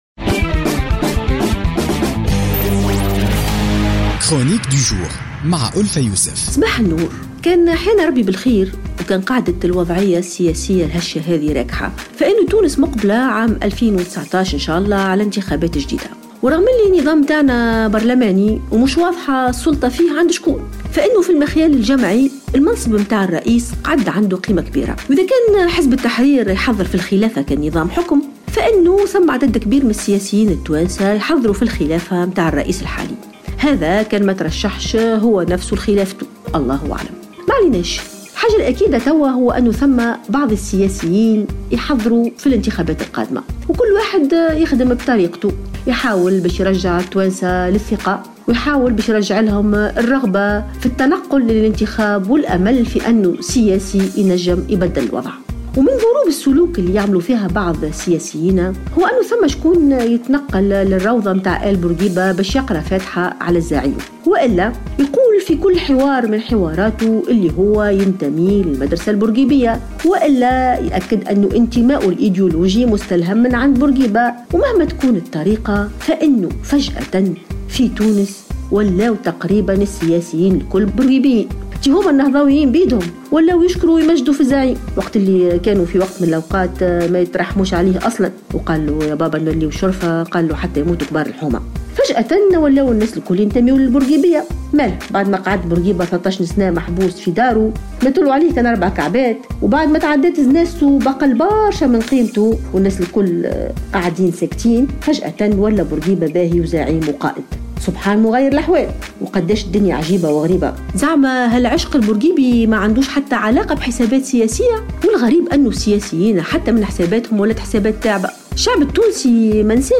تطرقت الكاتبة ألفة يوسف في افتتاحية اليوم الثلاثاء 21 فيفري 2017 إلى الإنتخابات...